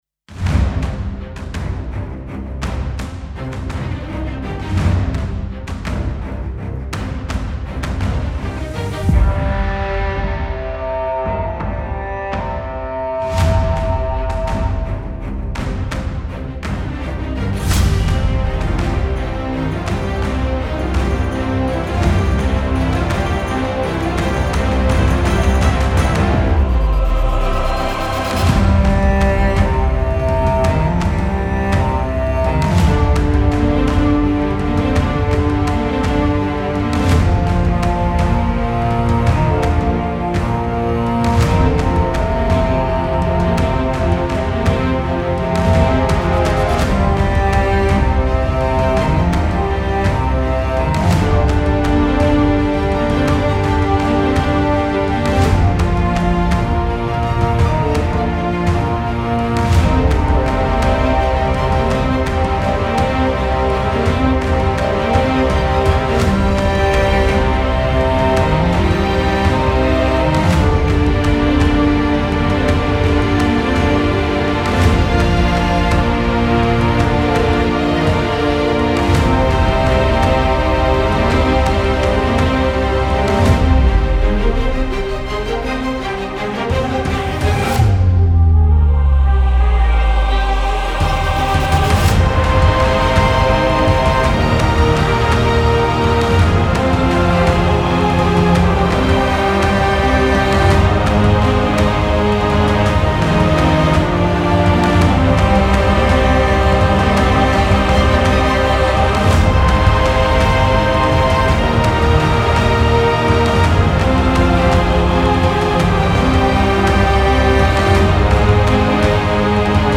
Music Remake.